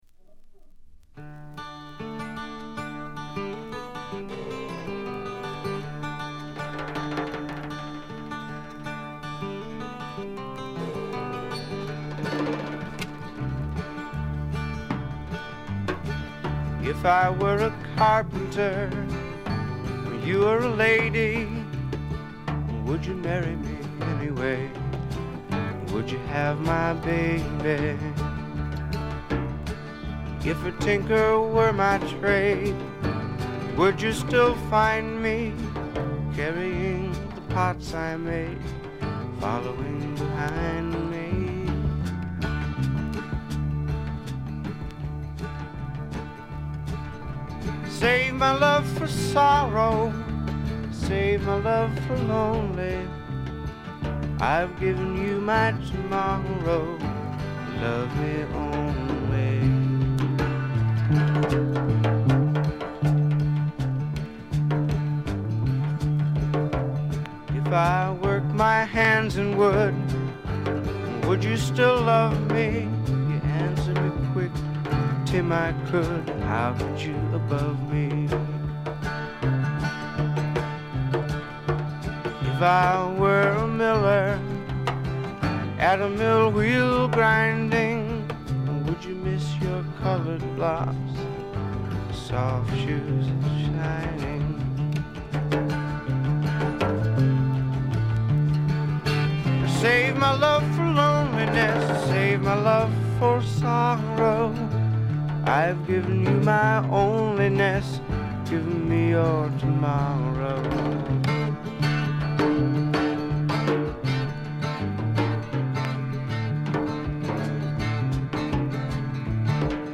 バックグラウンドノイズ、チリプチ多め大きめ。A1からA2にかけて大きなパチ音。
フォーク、ロック、ジャズ等を絶妙にブレンドした革新的ないでたちでの登場でした。
試聴曲は現品からの取り込み音源です。